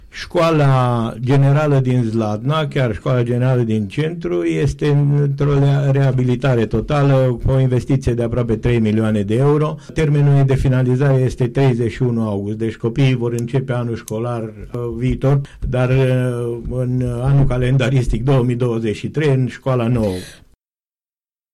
Primarul Zlatnei, Silviu Ponoran, a vorbit la Unirea FM despre această investiție.